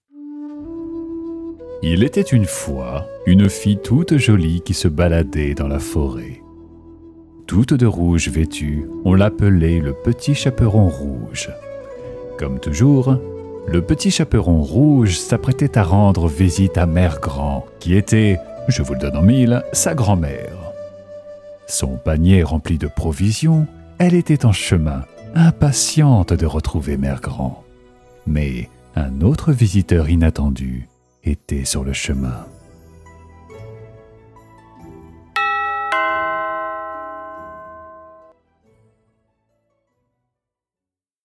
Voix off
30 - 40 ans - Basse